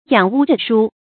仰屋着书 yǎng wū zhù shū
仰屋着书发音